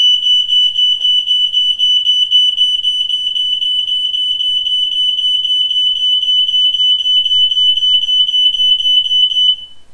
• Hitzewarnmelder (vernetzbar)
• Lautstärke im Test: 100,5 dBA
ei603c-hitzewarnmelder-alarm.mp3